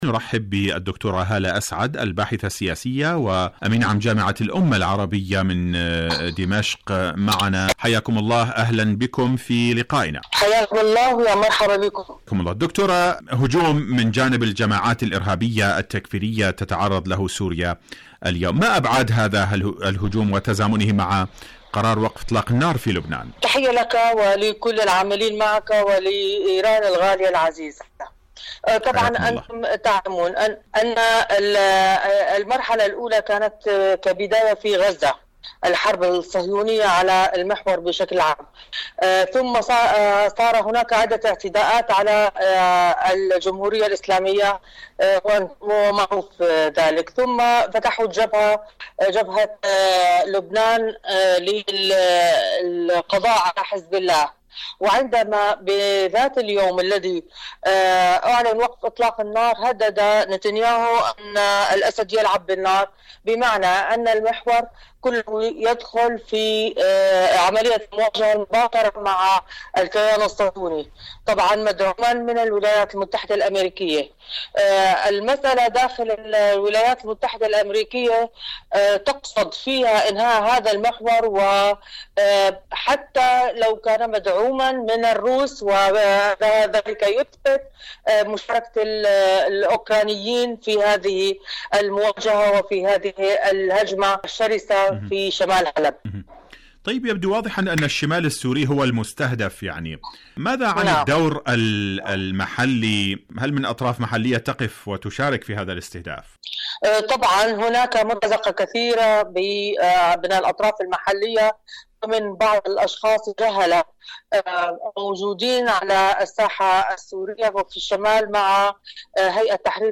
إذاعة طهران العربية برنامج حدث وحوار مقابلات إذاعية